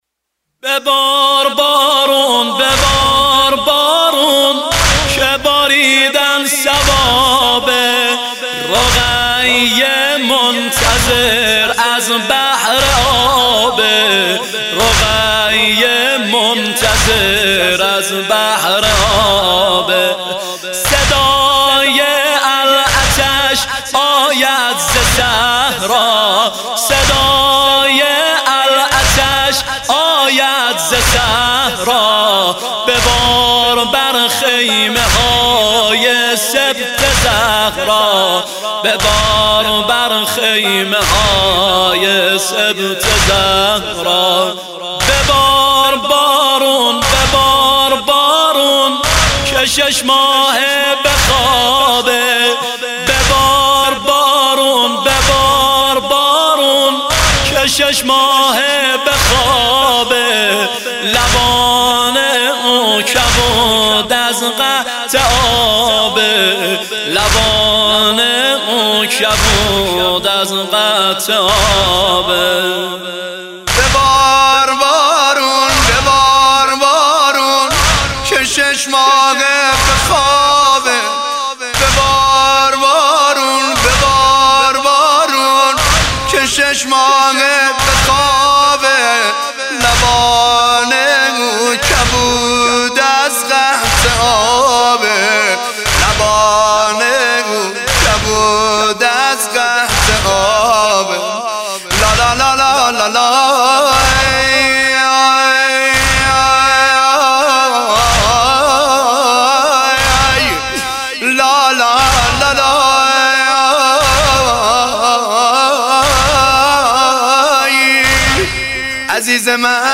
نوحه زنجیر زنی ببار بارون که باریدن ثوابه